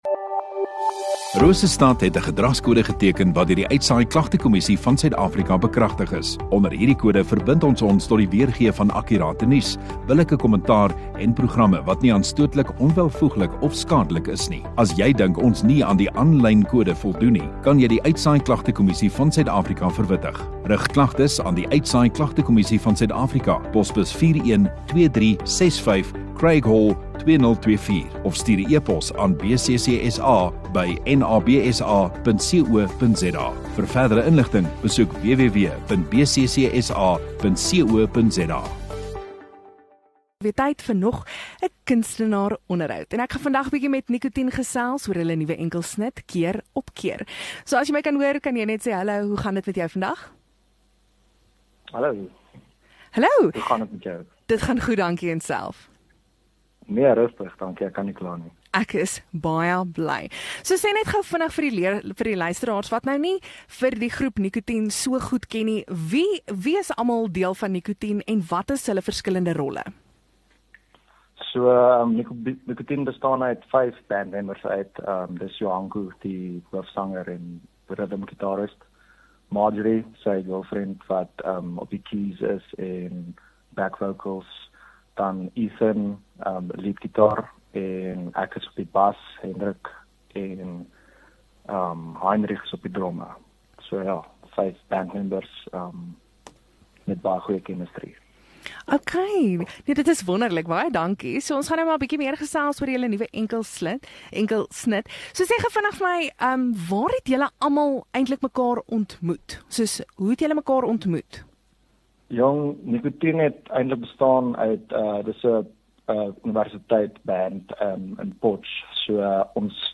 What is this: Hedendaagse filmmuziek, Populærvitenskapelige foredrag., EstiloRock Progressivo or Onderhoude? Onderhoude